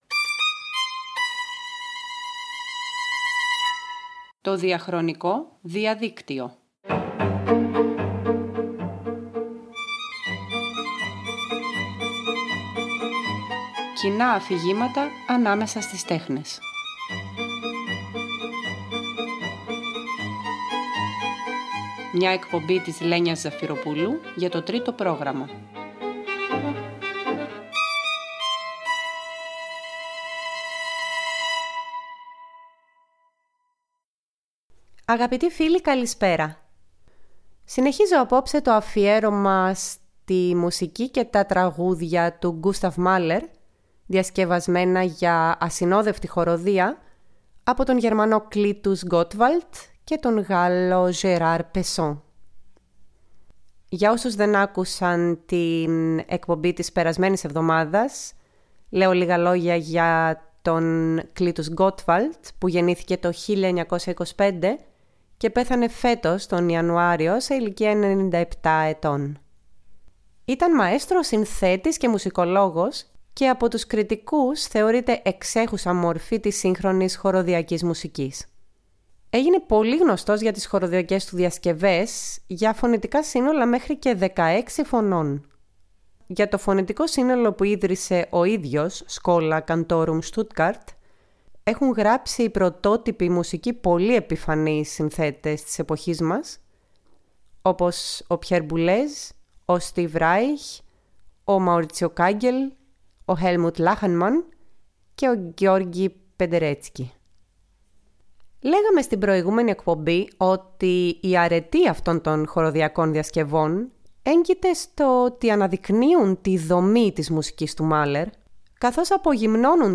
Ένα αφιέρωμα στις διασκευές για ασυνόδευτη χορωδία που ο Clytus Gottwald και ο Gérard Pesson έχουν κάνει σε τραγούδια και συμφωνική μουσική του Gustav Mahler. Απογυμνώνοντας τη μαλερική παρτιτούρα από τα ορχηστρικά χρώματα και αποσπώντας με την πολυφωνία την προσοχή μας από το ποιητικό κείμενο, οι αριστοτεχνικές αυτές διασκευές λειτουργούν ως αρχιτεκτονικές μακέτες όπου σε τομή μπορεί κανείς να ακούσει κάθετα όλο το αρχικό οικοδόμημα του Μάλερ.